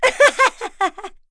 Laudia-Vox_Happy2.wav